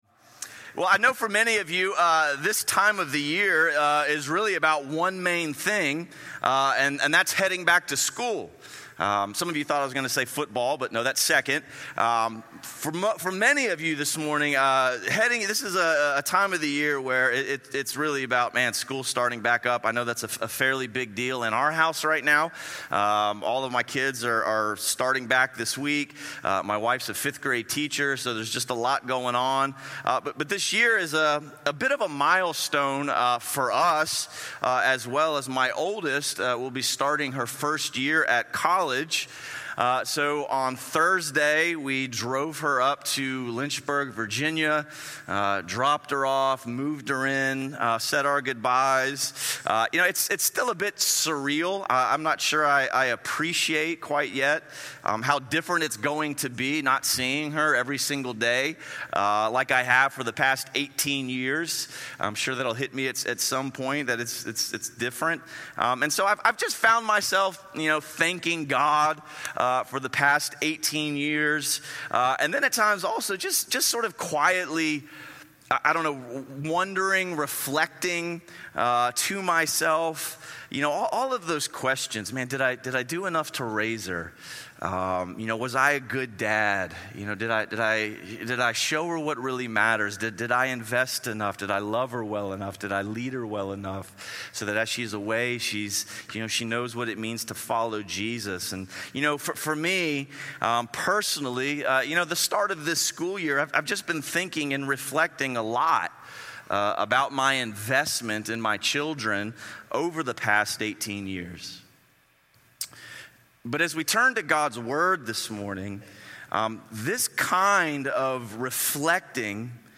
Investing In The Next Generation | Crossway Community Church